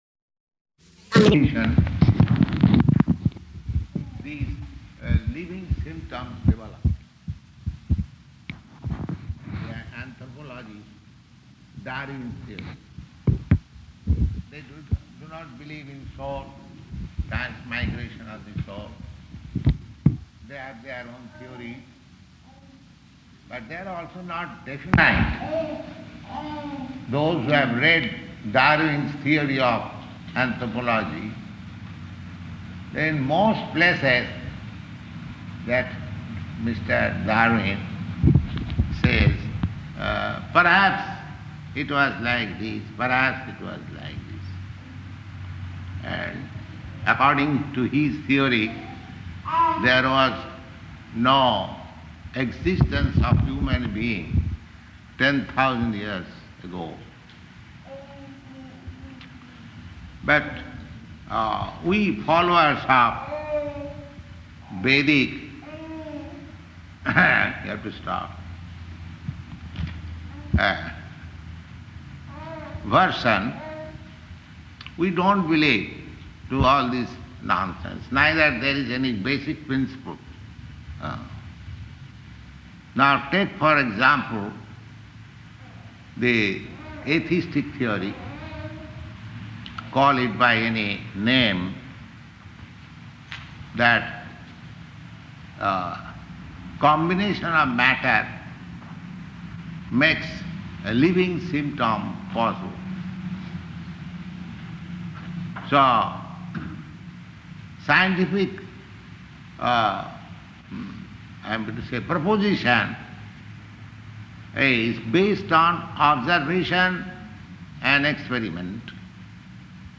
Bhagavad-gītā 2.26 --:-- --:-- Type: Bhagavad-gita Dated: December 6th 1968 Location: Los Angeles Audio file: 681206BG-LOS_ANGELES.mp3 Prabhupāda: [indistinct] ...these living symptoms develop.